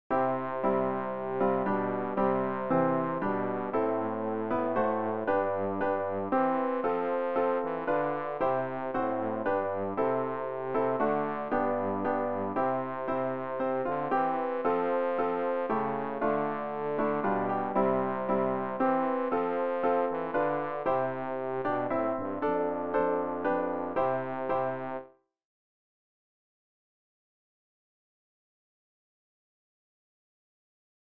bass-rg-100-erfreue-dich-himmel-erfreue-dich-erde.mp3